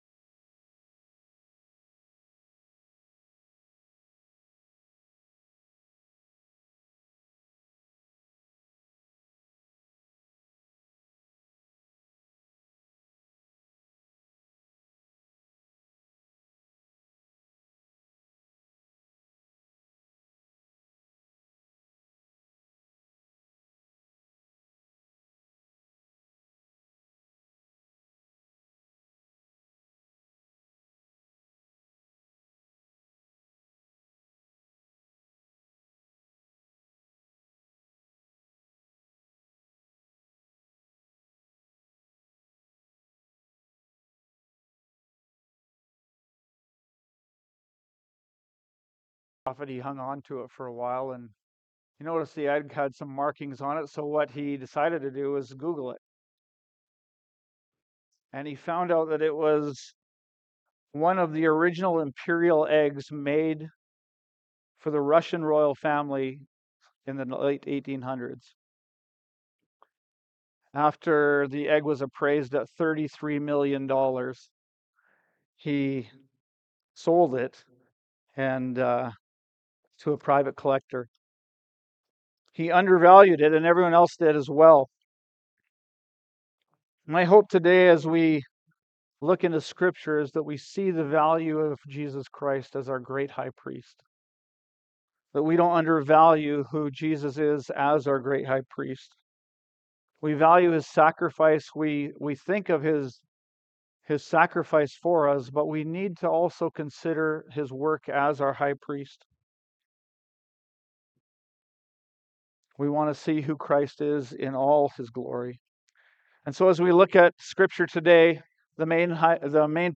Sunday Morning Sermons